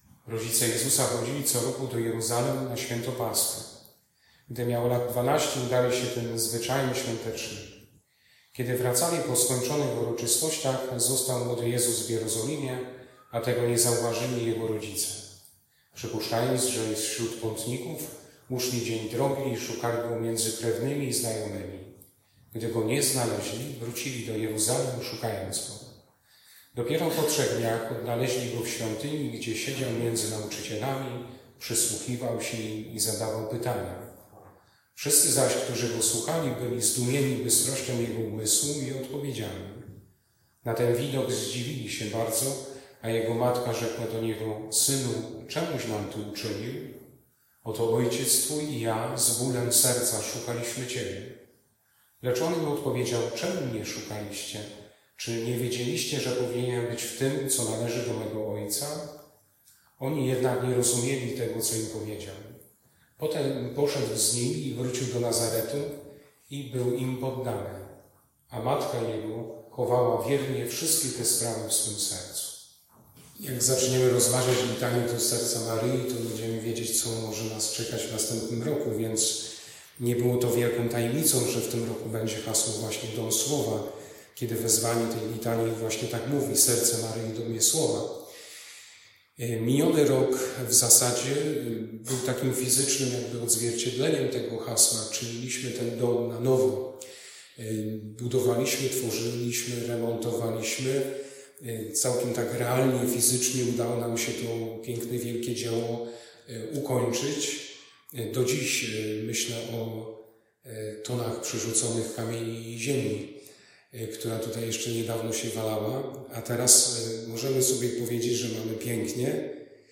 imieniny parafii 2025 | wspólnota Jerzykowo
słowo po Ewangelii